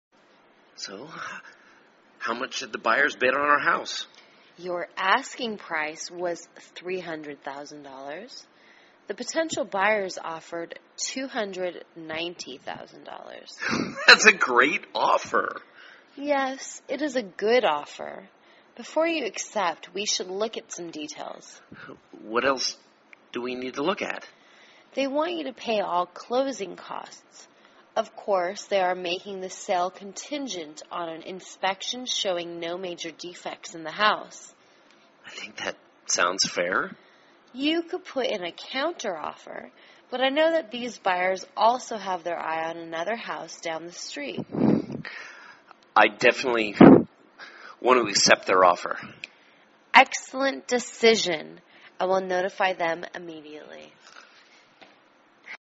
卖房英语对话-Accepting an Offer(1) 听力文件下载—在线英语听力室